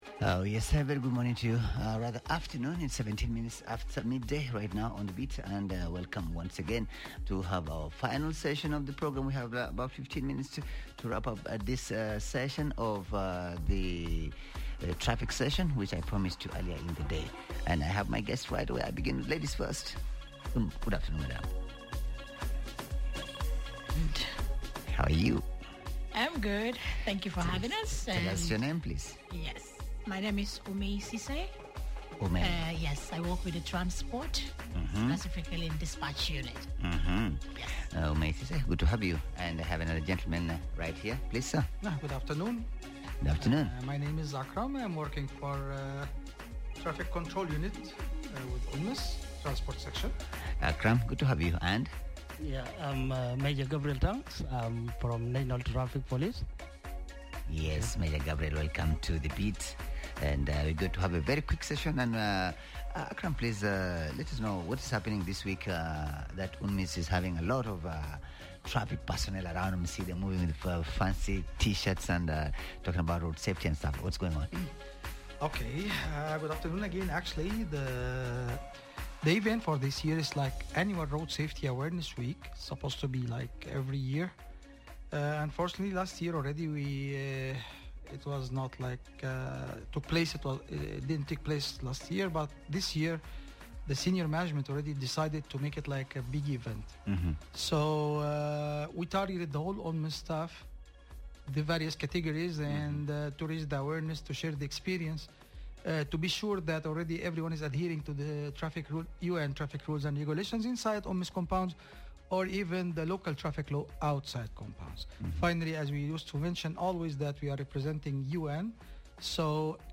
In our second conversation on promoting a culture of safe driving